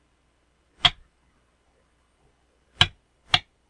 Sonidos " Caída Movida Basurero
描述：垃圾桶音效